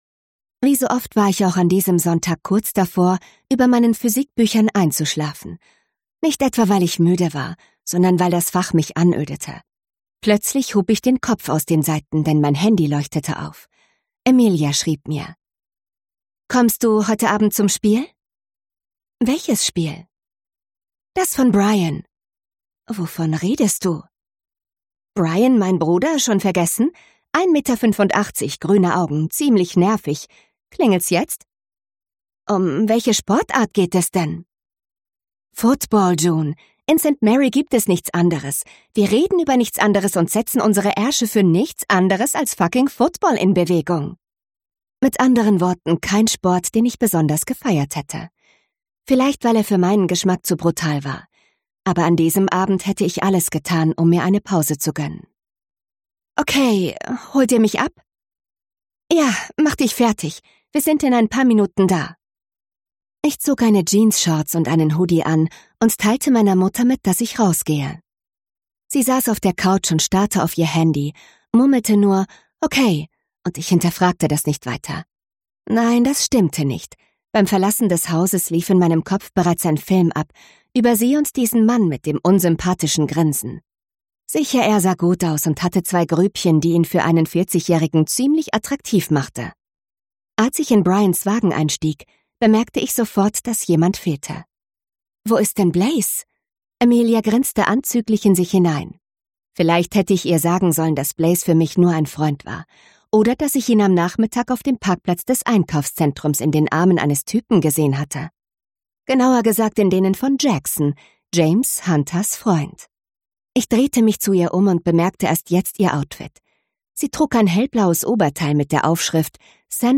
Ausgabe: Ungekürzte Lesung, Hörbuch Download